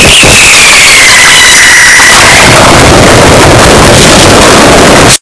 ROJAO MAIS ESTOURADO QUE O SUPER ESTOURADO - Botão de Efeito Sonoro